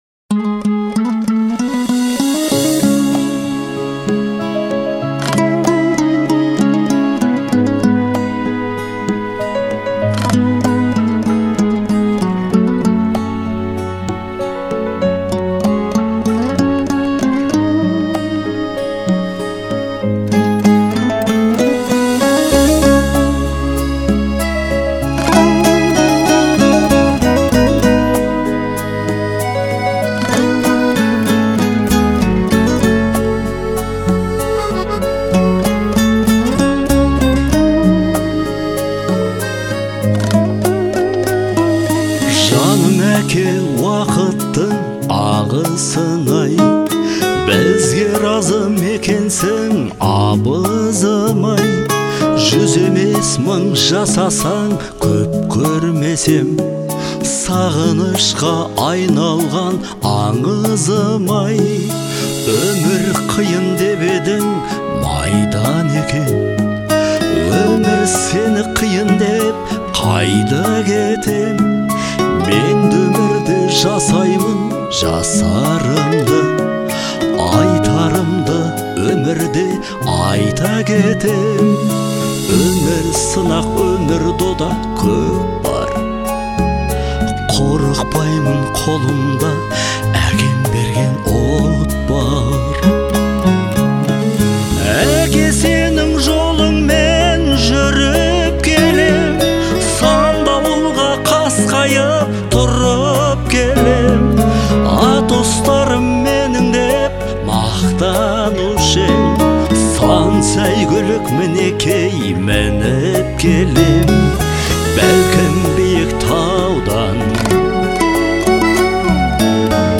относится к жанру казахской эстрадной музыки